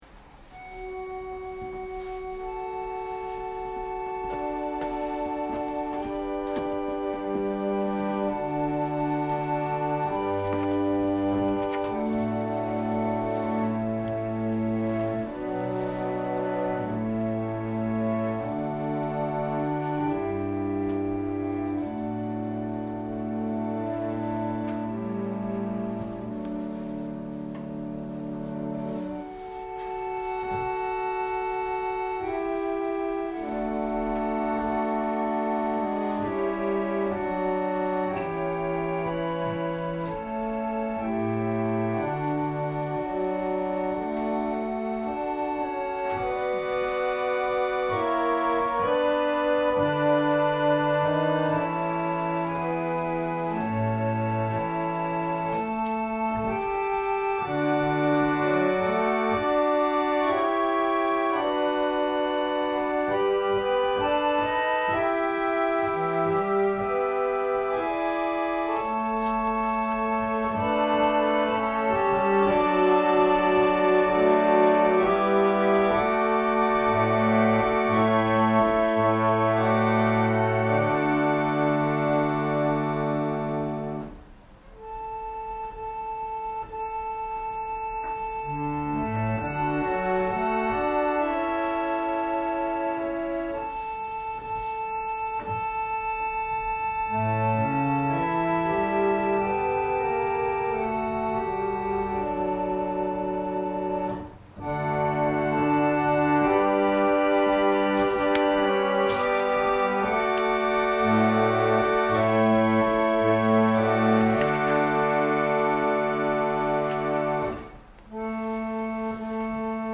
私たちは毎週日曜日10時20分から12時まで神様に祈りと感謝をささげる礼拝を開いています。
音声ファイル 礼拝説教を録音した音声ファイルを公開しています。